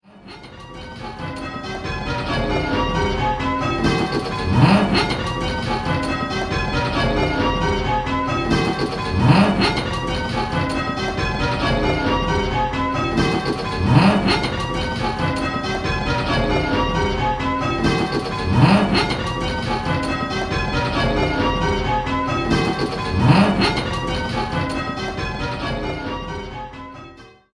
Where's the Treble: 10-Bell - Pebworth Bells